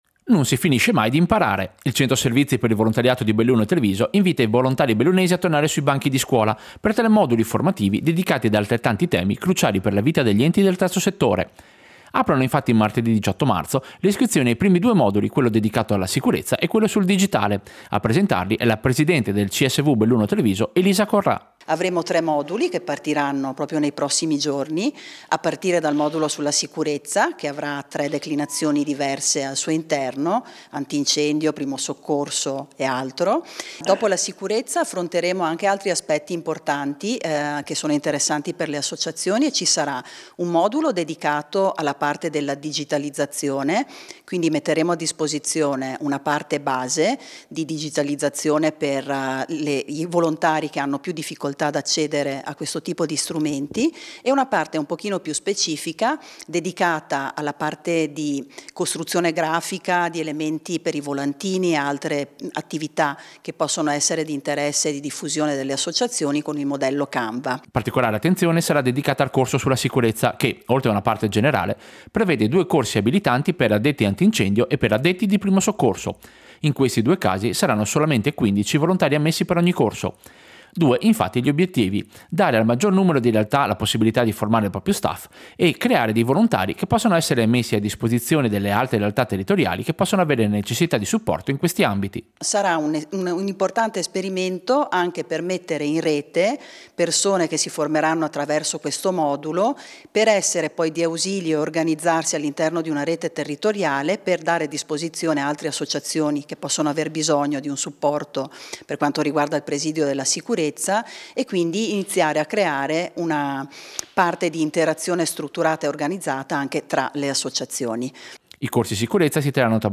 Servizio-Corsi-formazione-CSV.mp3